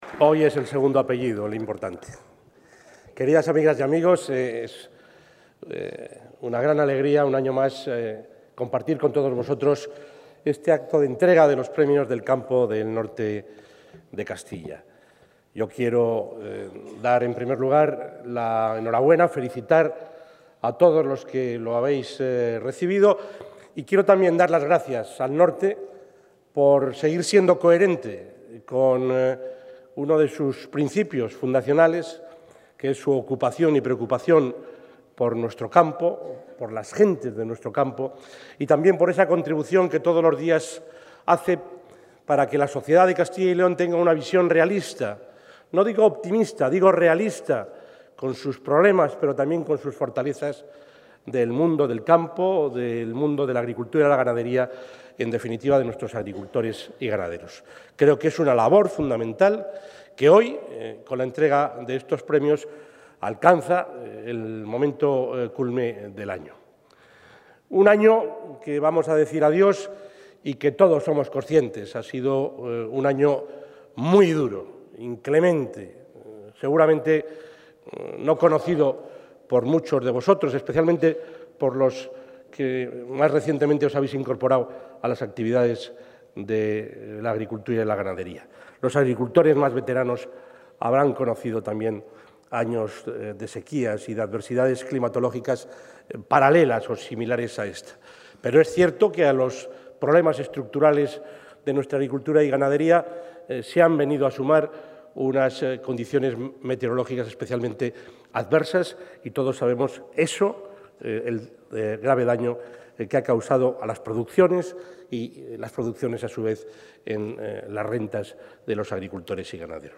Audio presidente de la Junta de Castilla y León.
El presidente de la Junta de Castilla y León, Juan Vicente Herrera, ha asistido esta tarde a la entrega de la cuarta edición de los premios del Campo de El Norte de Castilla, donde ha avanzado que las grandes líneas europeas apuntan a una Política Agraria Común más ágil y sencilla que refuerce la apuesta por la incorporación de jóvenes, además de permitir a cada país definir su propia estrategia de la PAC.